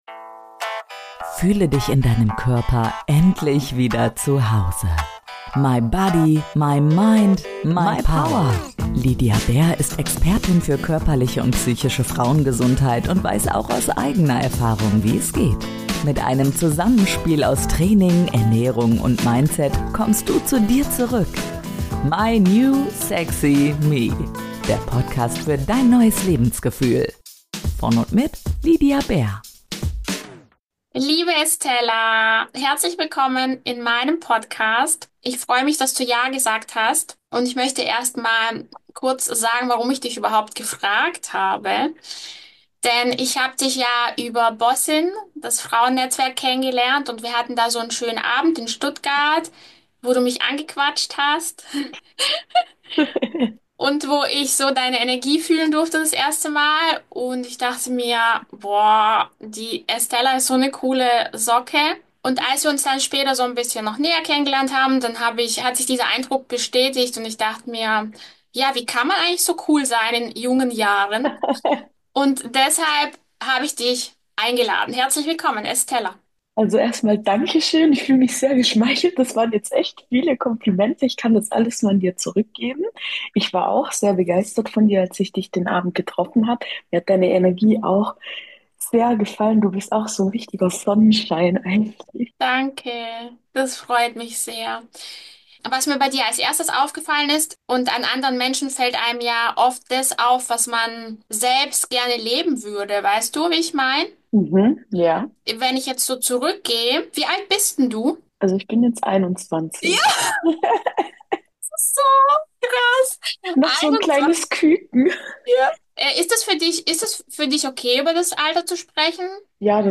Unser Gespräch ist ein ehrlicher Austausch unter Kolleginnen – locker, offen und voller Tiefe.